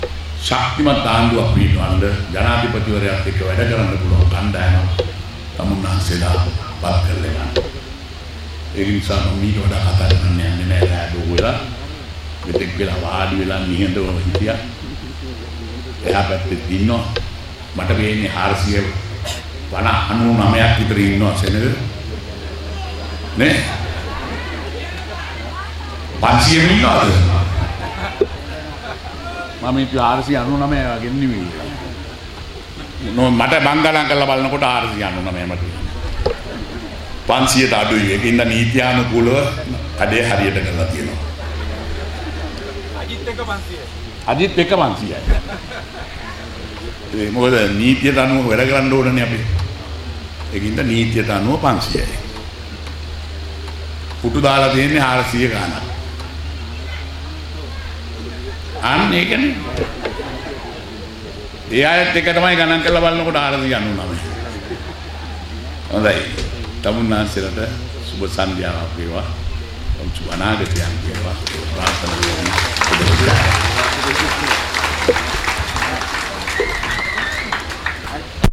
අගමැති මහින්ද රාජපක්ෂ මහතා ගේ ප්‍රධානත්වයෙන් ඊයේ (05) සවස අම්බලන්තොට මාමඩල ප්‍රදේශයේ පැවති මැතිවරණ ප්‍රචාරක රැළියේදී අගමැති මහින්ද රාජපක්ෂ මහතා විසින් නිරෝධායන නීතිය උල්ලංඝණය කිරීම සමච්චලයට ලක් කළ අතර ඔහුගේ සමච්චලයට පැමිණ සිටි පිරිස කොක් හඩලා සිනාසෙන අයුරුද දකින්නට හැකි වීය.
අගමැතිවරයා ගේ හඩ පටය